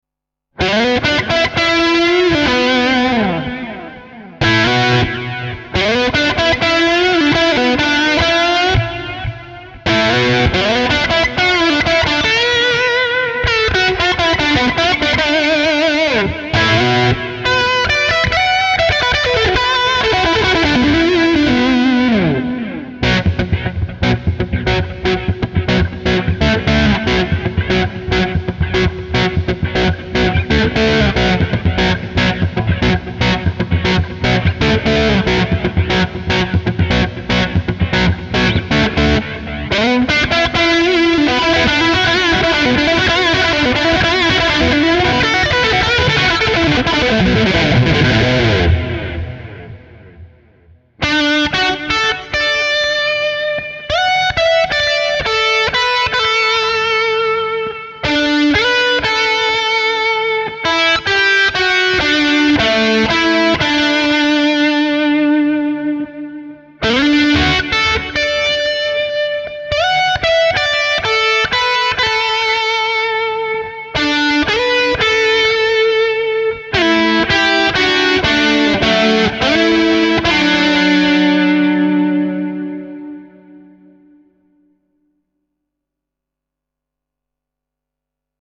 Tradycyjnie wykonuje się go na trąbce.